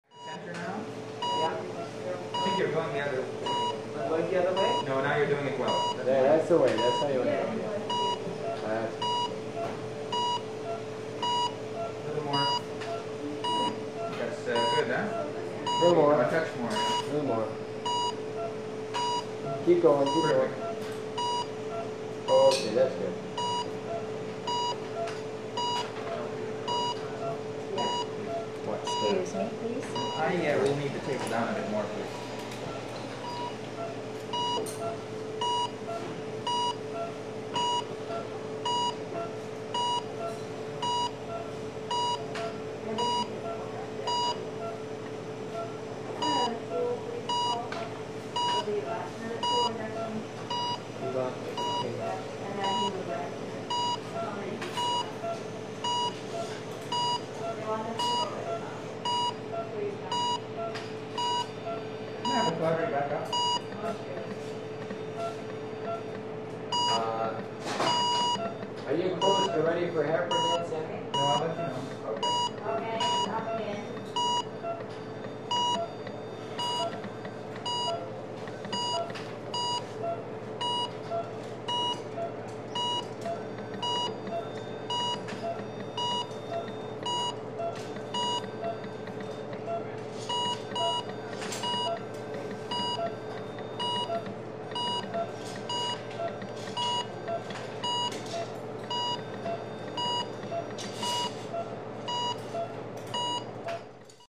Больница операция кардиомонитор голоса врачей